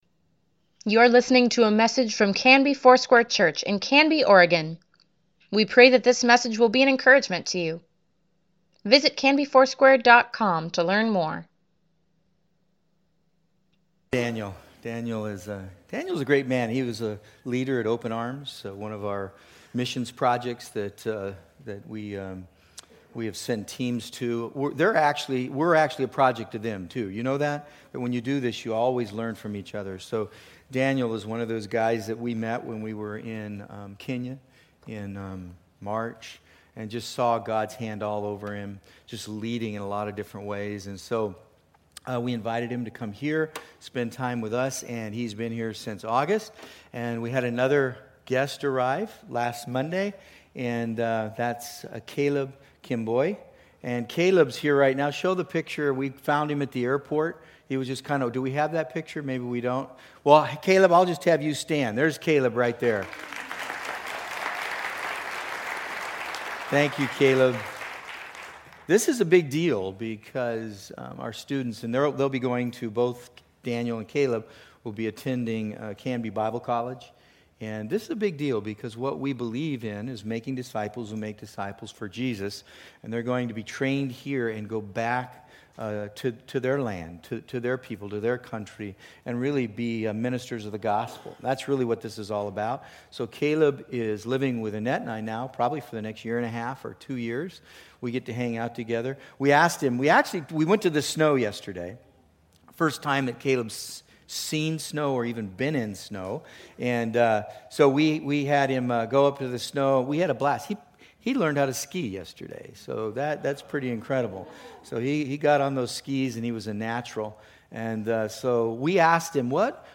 Weekly Email Water Baptism Prayer Events Sermons Give Care for Carus Hope Fulfilled December 23, 2018